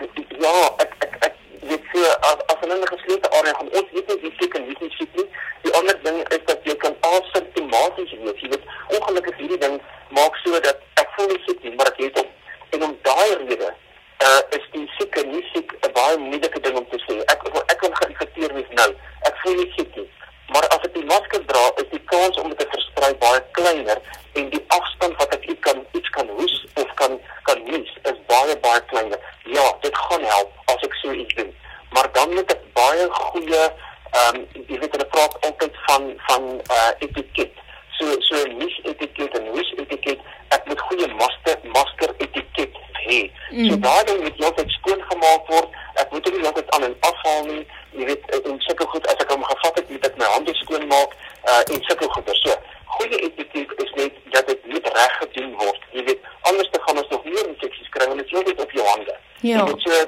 Stemopname